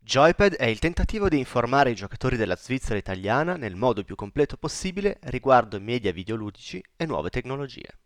Qua sotto trovate un comparativo registrato con tre microfoni: il microfono integrato della webcam Logitech Brio 4K, il microfono delle cuffie LogitechG Pro X e Razer Seiren.